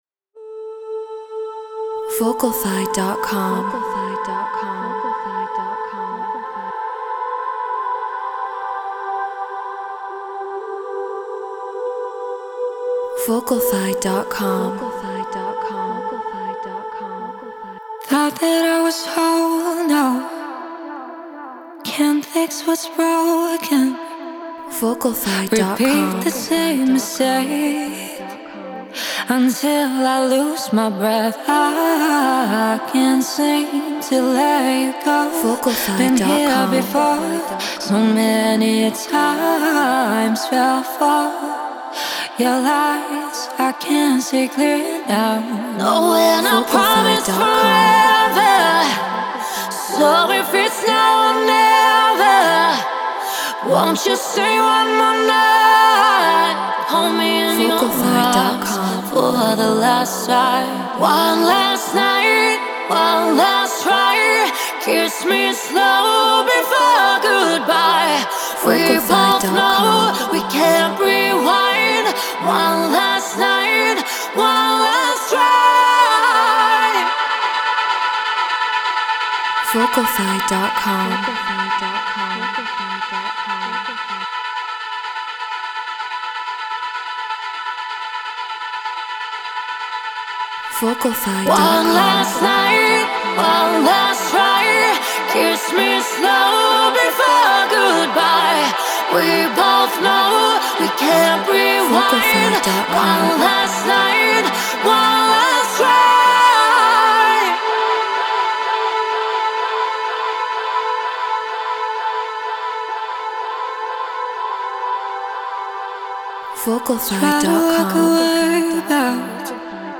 House 126 BPM Amin